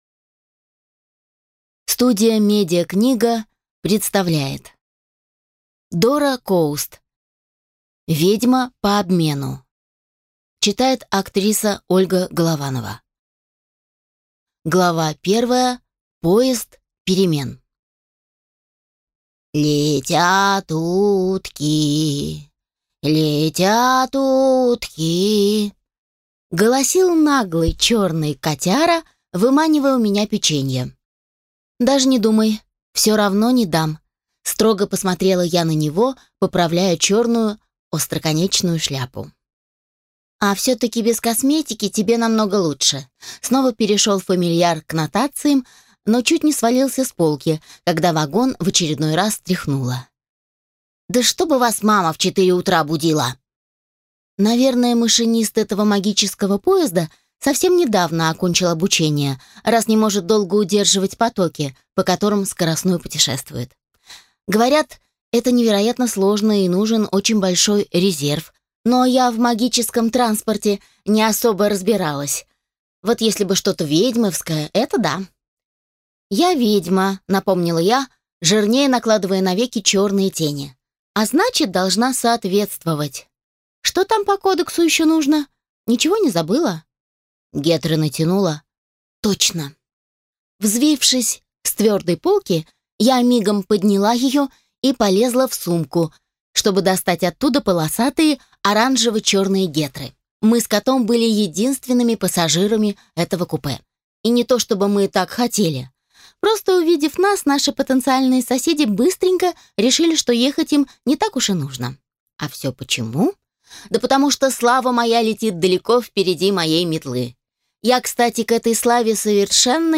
Аудиокнига Ведьма по обмену | Библиотека аудиокниг